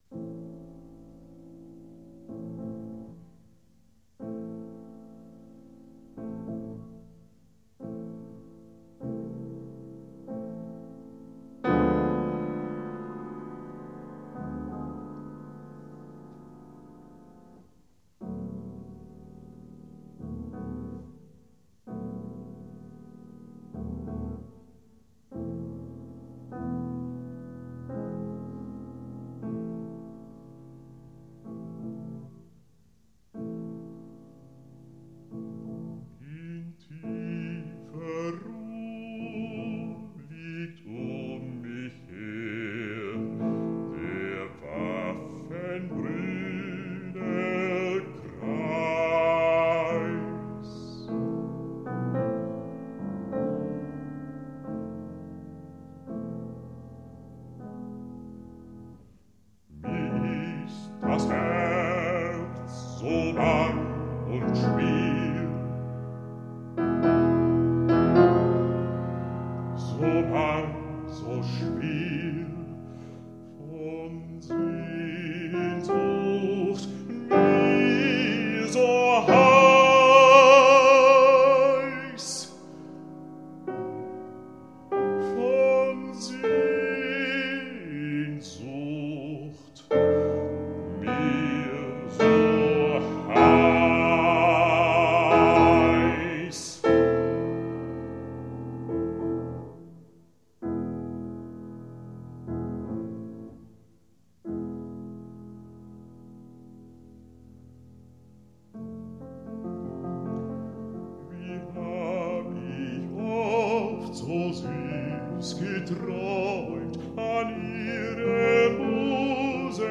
A Lasco-Bibliothek Emden, Flügel